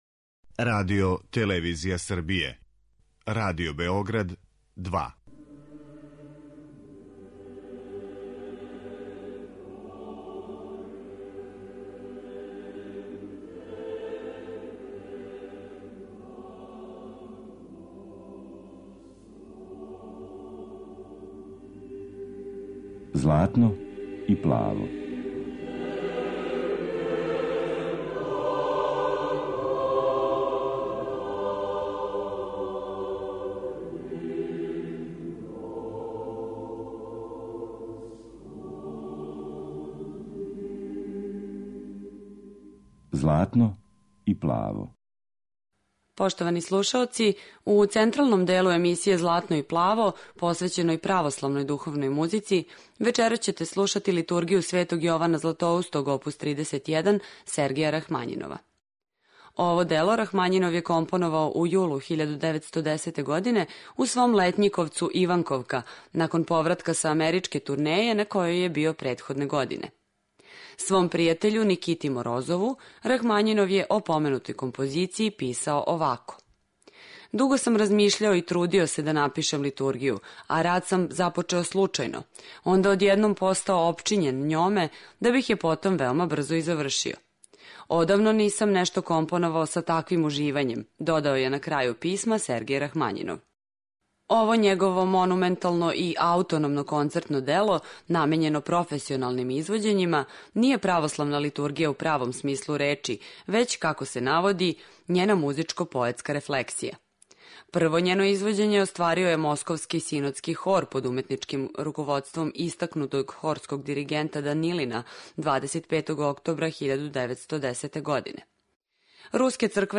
У овонедељној емисији православне духовне музике, централни део емисије посвећен је Литургији оп. 31, Сергеја Рахмањинова.